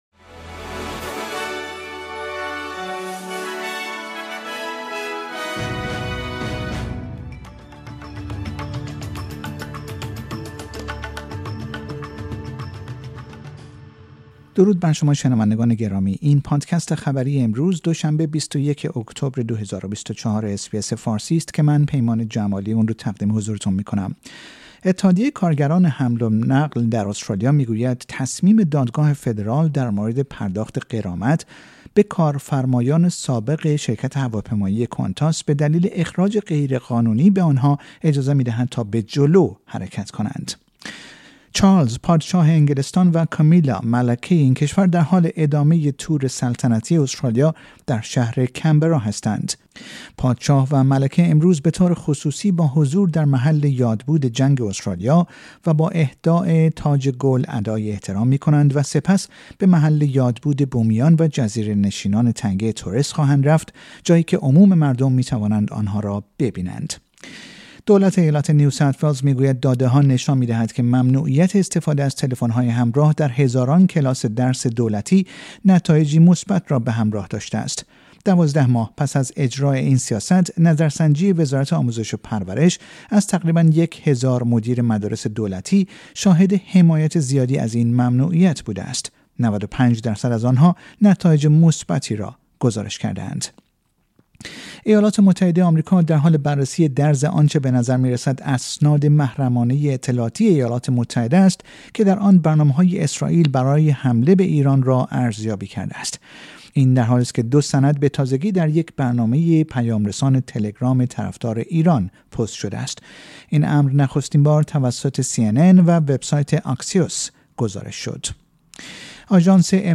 در این پادکست خبری مهمترین اخبار استرالیا در روز دوشنبه ۲۱ اکتبر ۲۰۲۴ ارائه شده است.